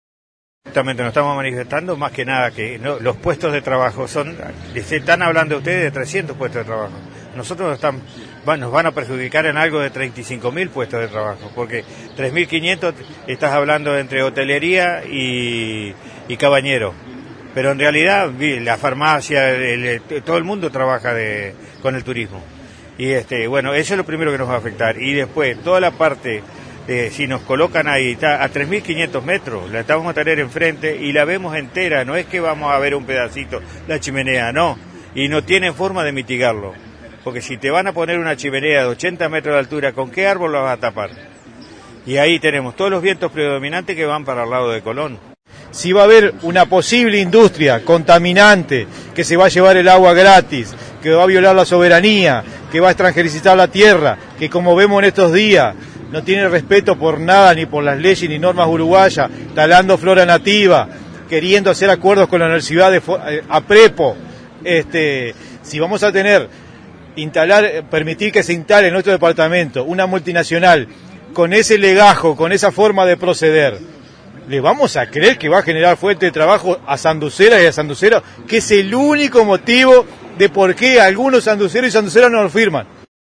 MANIFESTACION-AMBIENTALISTAS-COLON-EN-JUNTA-PAYSANDU.mp3